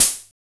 Machine_OpenHat.wav